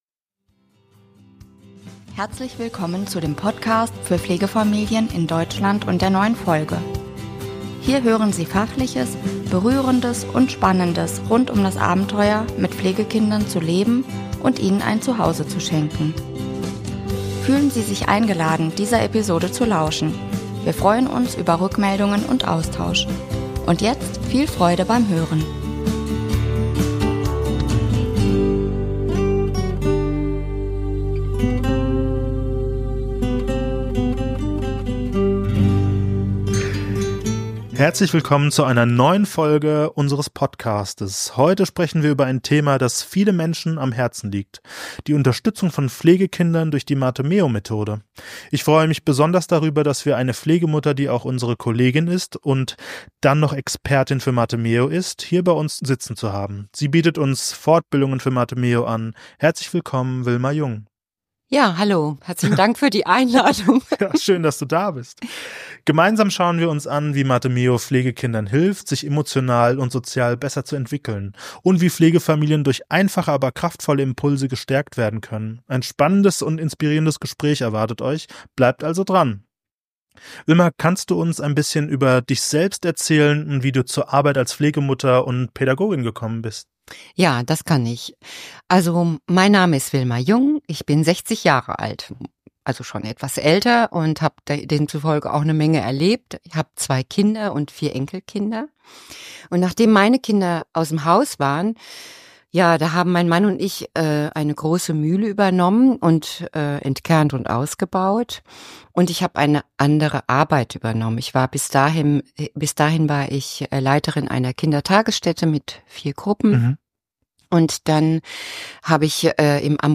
Ein spannendes und inspirierendes Gespräch erwartet euch – hört rein!